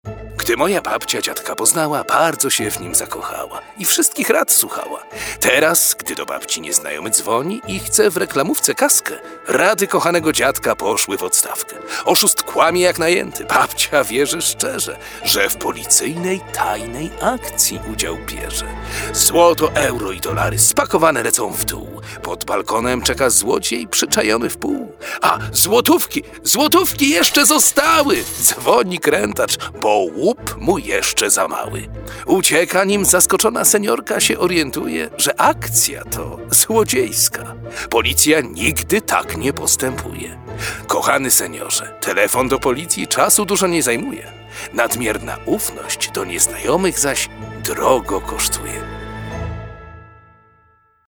Specjalnie na tę wyjątkową okazję chojniccy policjanci prewencji, w ramach działań profilaktycznych, wspólnie z Radiem Weekend FM przygotowali radiowy spot skierowany do seniorów. Przestrzegają w nich jak działają oszuści podszywający się pod bliskich lub samych policjantów. Policyjne dykteryjki odczytał jeden z najbardziej znanych, polskich lektorów Pan Rino Pawletta.